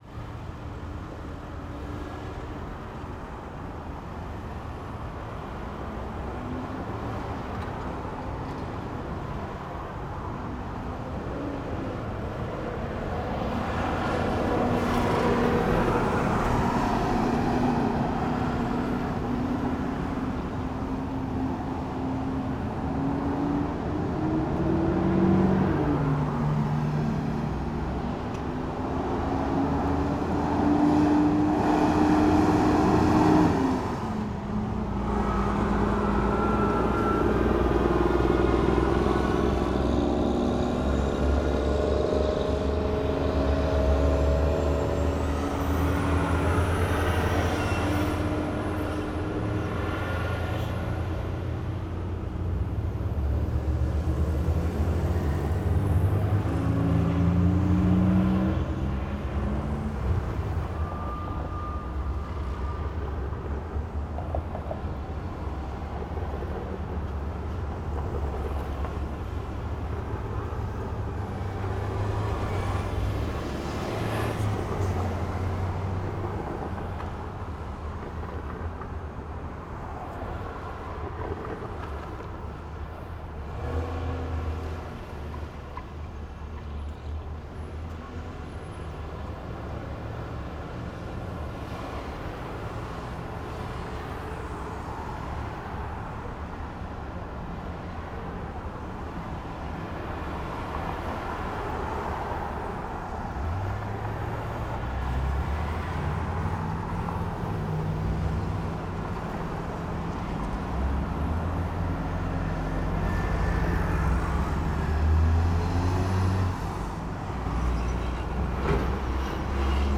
Traffic,Intersection,Streetcar Tracks,Wide POV,Hill,Mid Day,Medium,Trucks,Toil,D50.wav